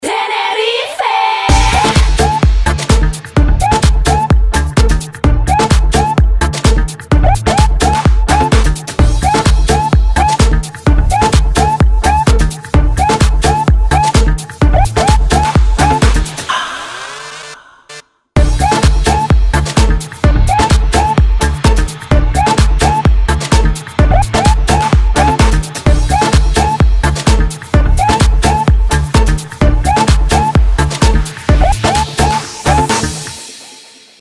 ритмичные